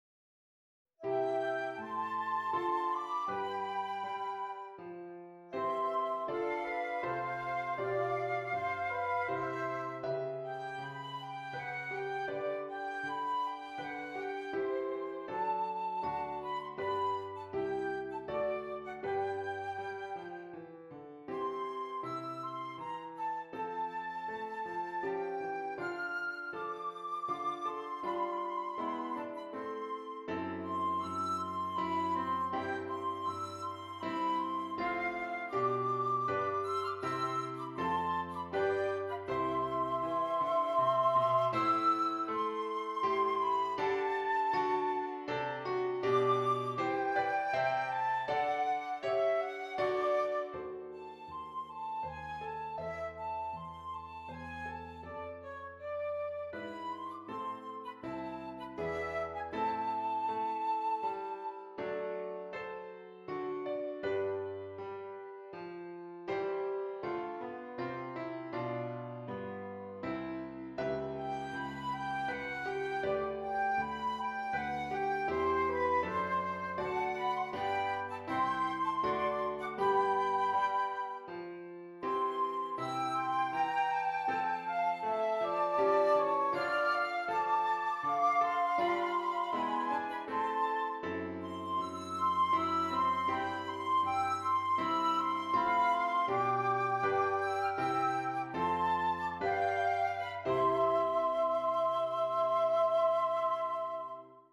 2 Flutes and Piano